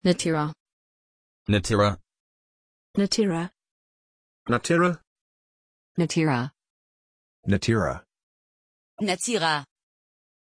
Pronunciation of Natyra
pronunciation-natyra-en.mp3